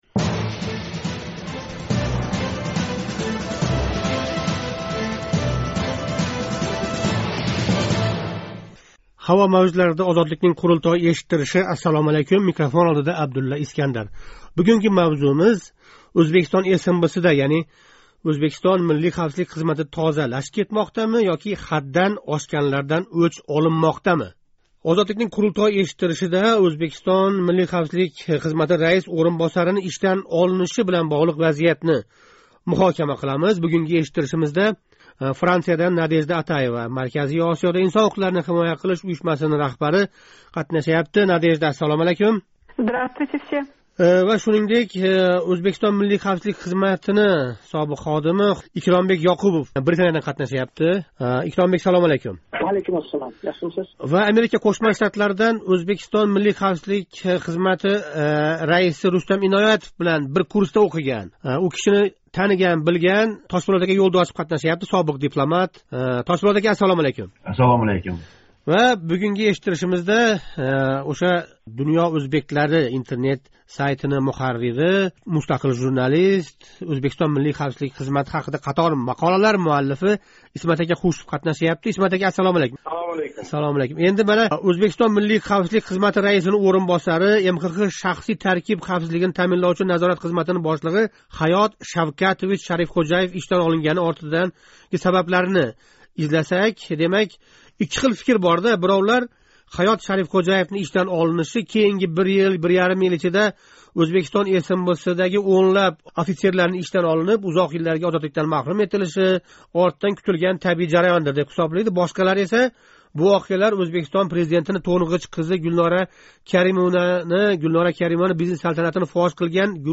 Озодликнинг Қурултой эшиттиришида Ўзбекистон МХХ раис ўринбосарининг ишдан олиниши билан боғлиқ вазиятни муҳокама қилдик. МХХ шахсий таркиб хавфсизлигини таъминловчи назорат хизматининг бошлиғи Ҳаëт Шавкатович Шарифxўжаев ишдан олингани ортидаги сабаб ва саволларни Қурултой қатнашчилари излади.